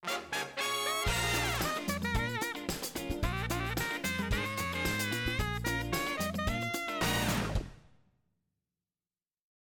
musique-victoire.mp3